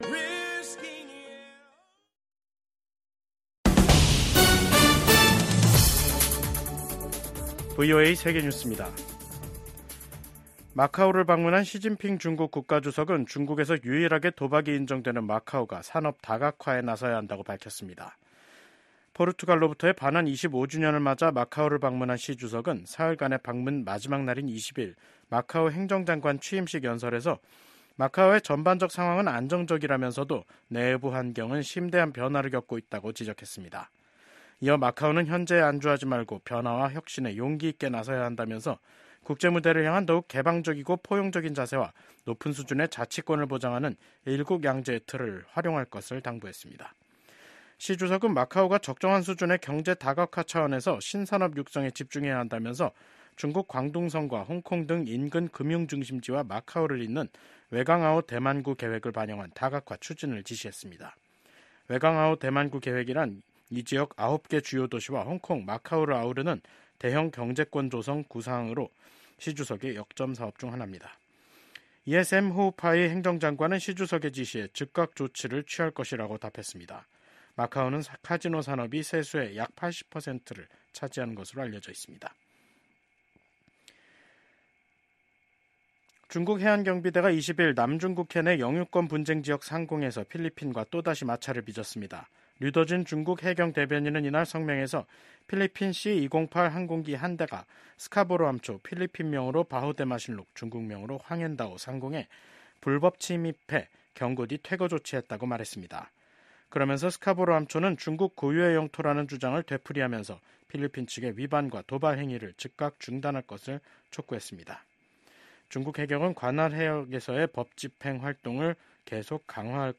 VOA 한국어 간판 뉴스 프로그램 '뉴스 투데이', 2024년 12월 20일 3부 방송입니다. 미국 국무부는 우크라이나 전쟁에 북한군을 투입한 김정은 국무위원장의 국제형사재판소(ICC) 제소 가능성과 관련해 북한 정권의 심각한 인권 유린 실태를 비판했습니다. 북한이 한국의 대통령 탄핵 정국과 미국의 정권 교체가 겹친 어수선한 연말 정세 속에서 도발적인 행동을 자제하는 양상입니다.